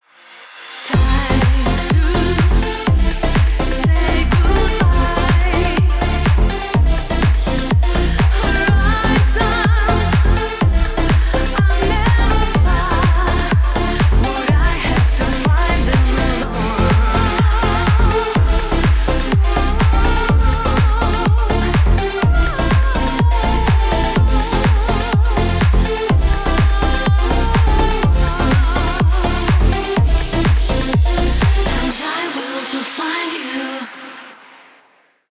Танцевальные # кавер
клубные